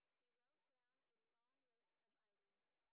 sp11_exhibition_snr10.wav